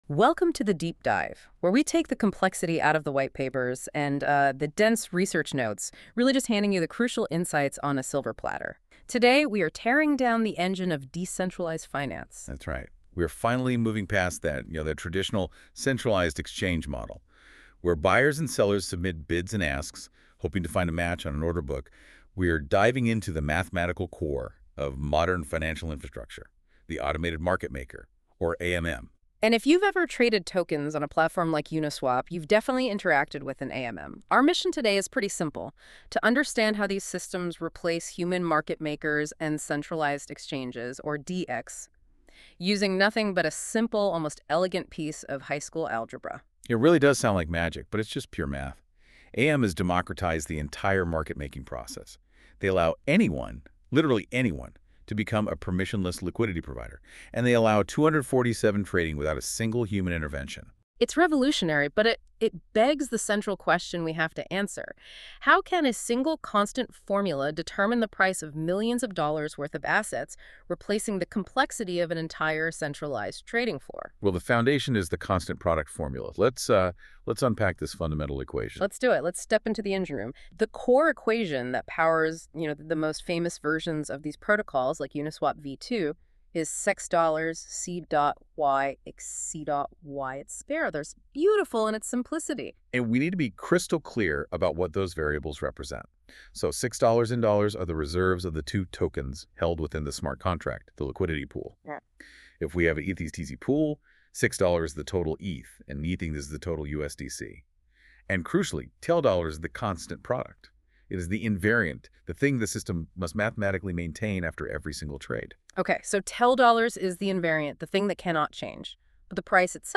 hashtag🎧 Lesson Podcast